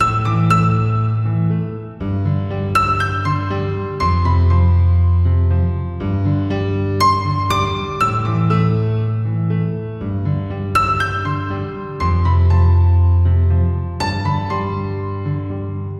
情感钢琴120bpm
Tag: 120 bpm RnB Loops Piano Loops 2.69 MB wav Key : C